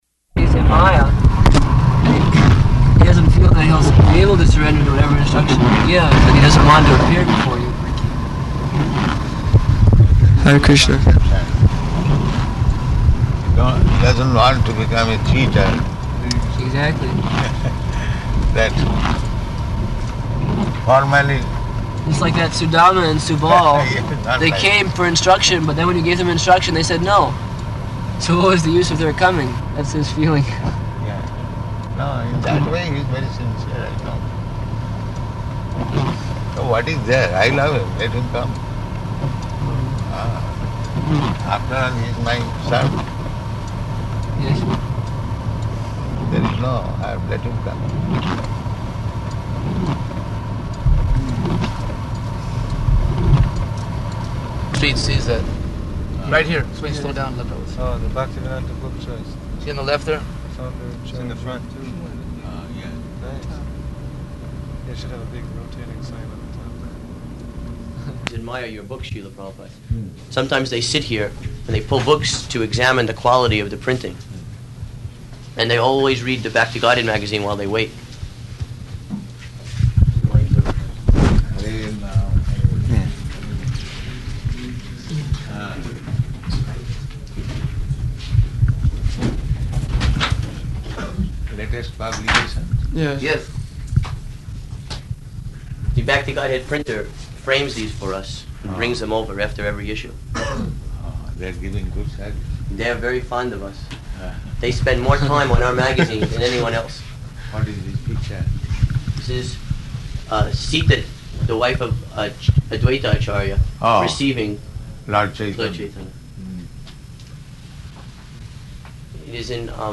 -- Type: Walk Dated: February 10th 1975 Location: Los Angeles Audio file